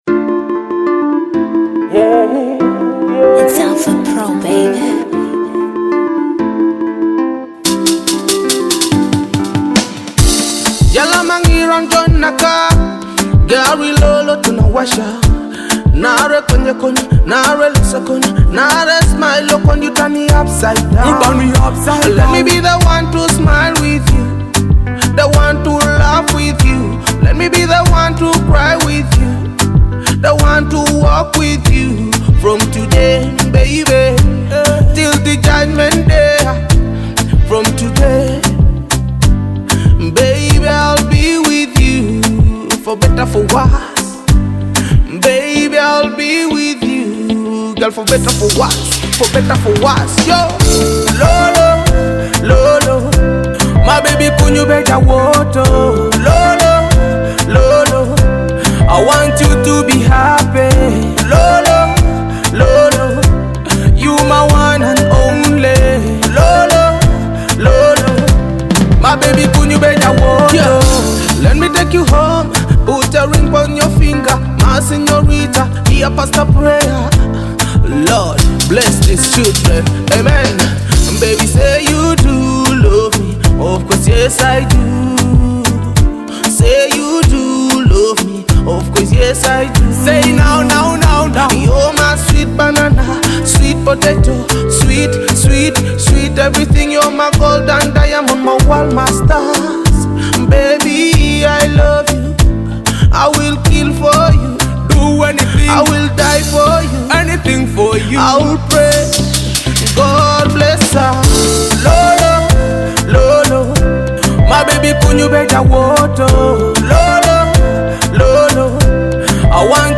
a captivating blend of rhythmic beats and powerful vocals.